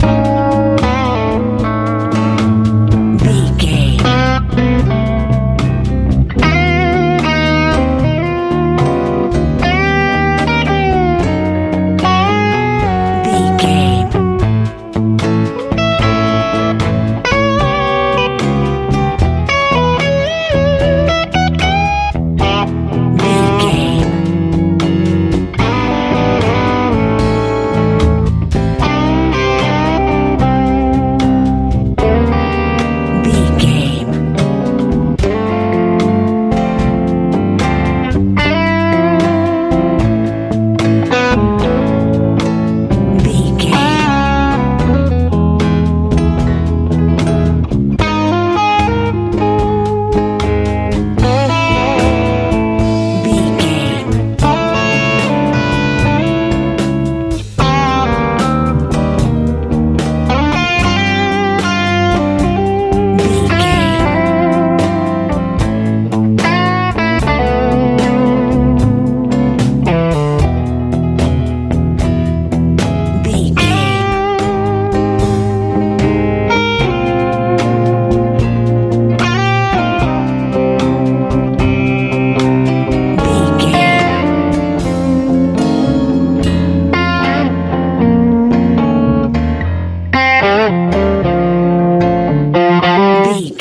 Ionian/Major
D♭
mellow
electric guitar
piano
bass guitar
drums
peaceful
relaxed